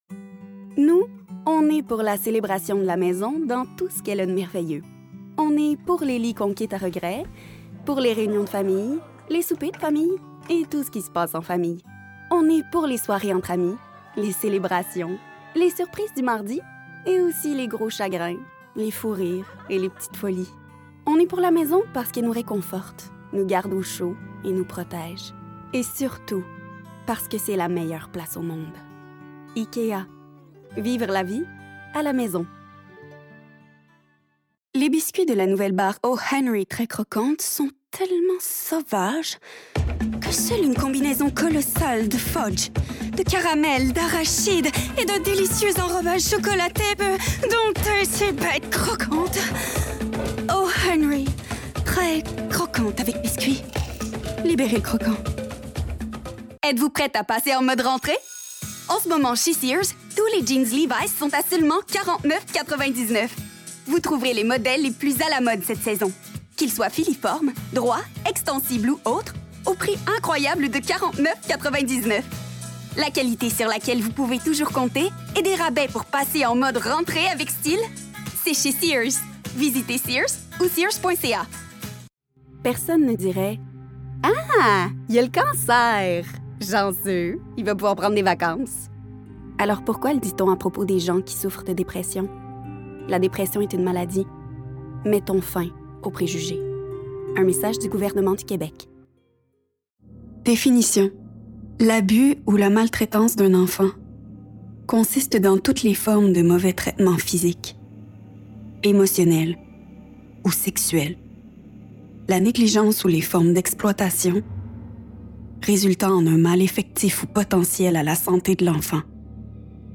Démo de voix
18-30 ans ⸱ 30-45 ans ⸱ Narration ⸱ Publicité